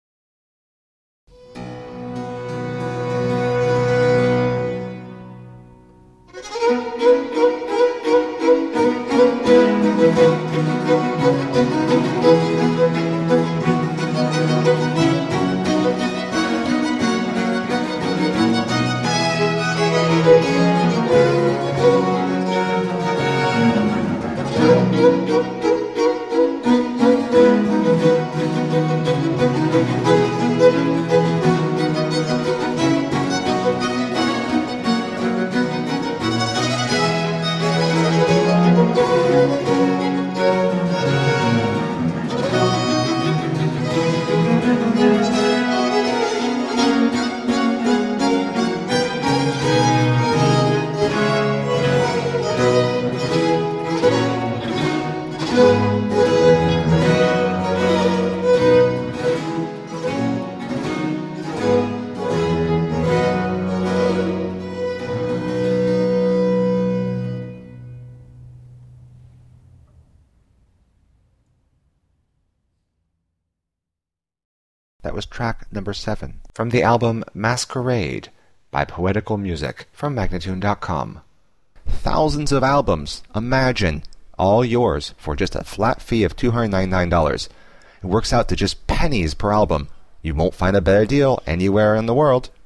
marvellously scintillating and exciting music
for violins, viola, and continuo
Classical, Baroque, Chamber Music, Classical Singing
Harpsichord, Viola da Gamba